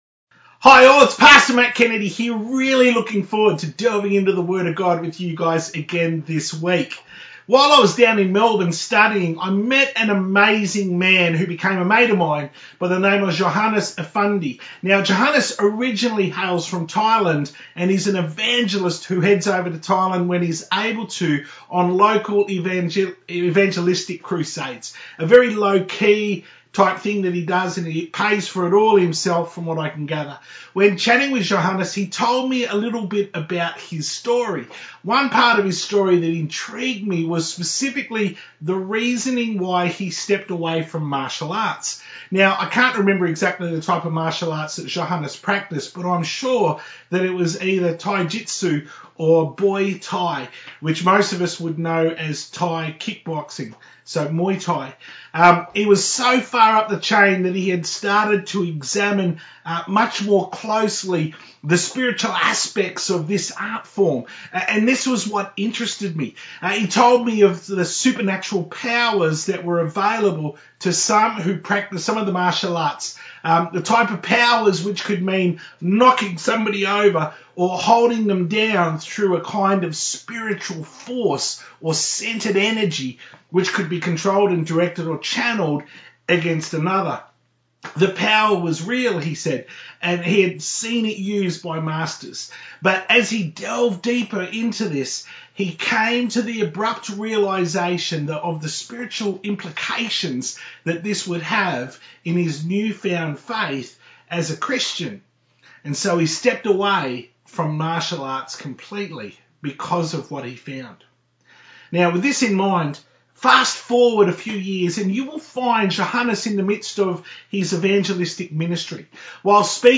To view the Full Service from 1st November 2020 on YouTube, click here.